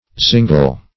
Search Result for " zingel" : The Collaborative International Dictionary of English v.0.48: Zingel \Zing"el\ (z[i^]ng"el), n. (Zool.)